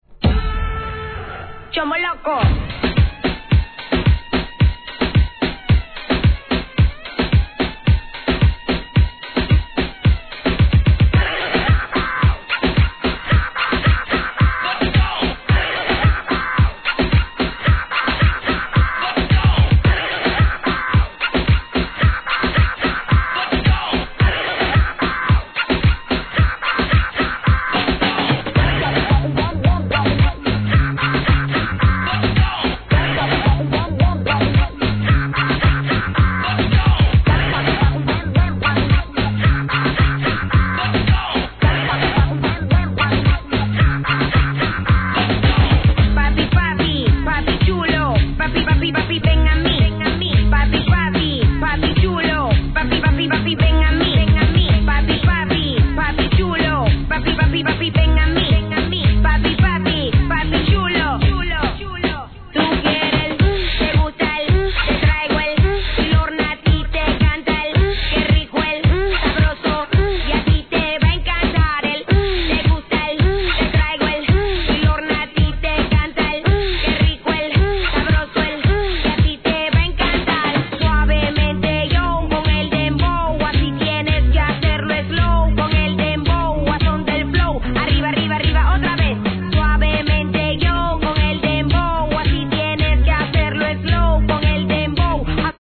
HIP HOP/R&B
REGGAETON調のウキウキなラガ・ダンスナンバー!!